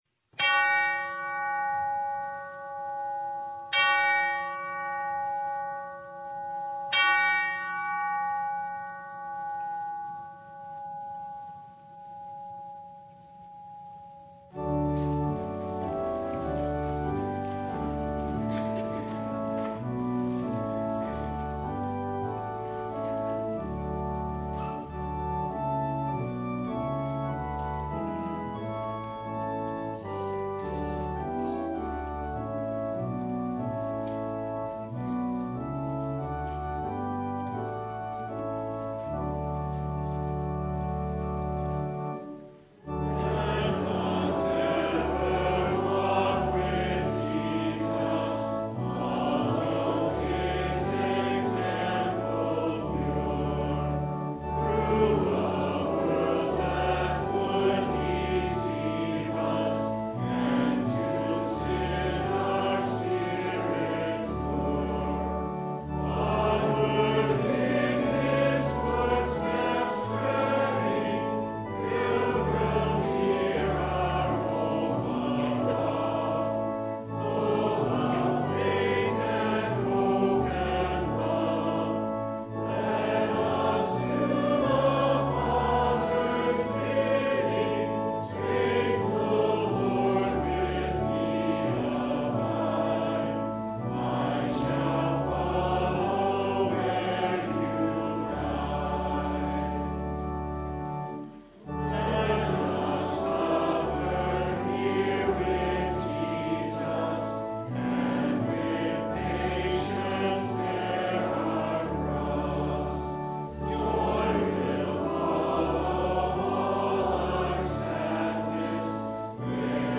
John 11:32-44 Service Type: Sunday From the time of Adam